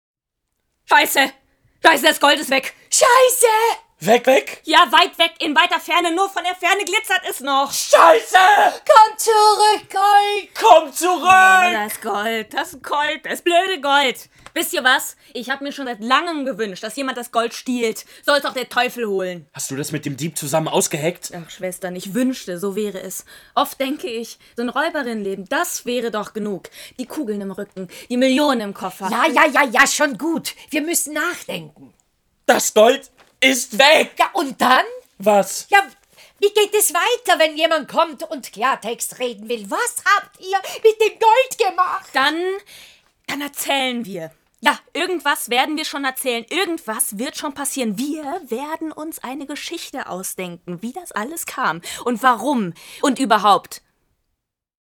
Ö1, Kulturjournal, Nachkritik, 29.04.2025